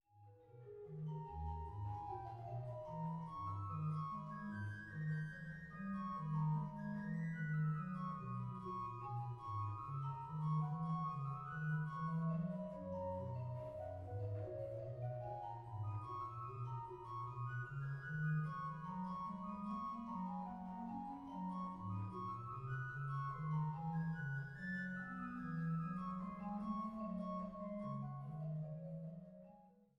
Bad Lausick